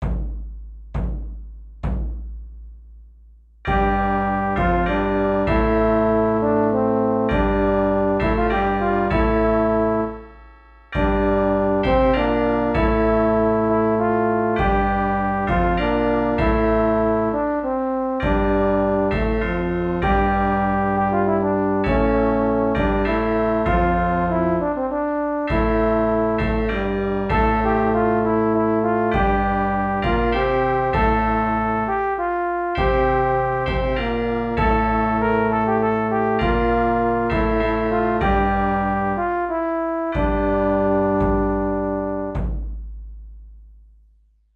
(motetus) |
004-motetus.mp3